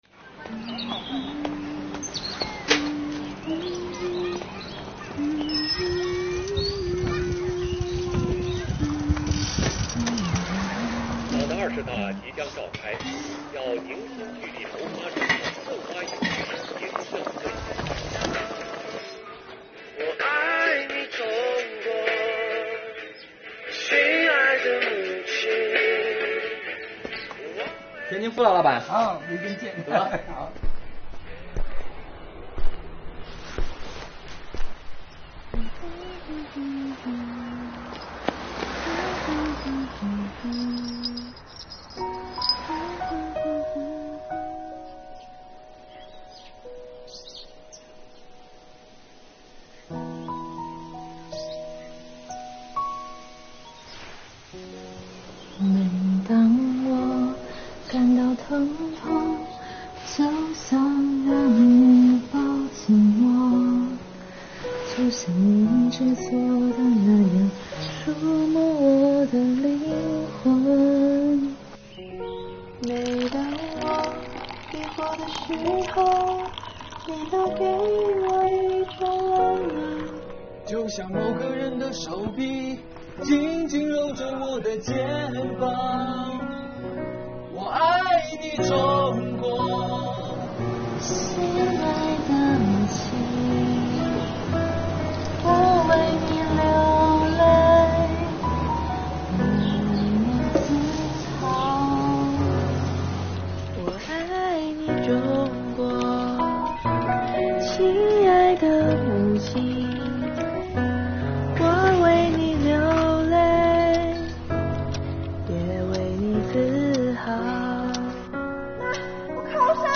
唱响一曲爱国赞歌~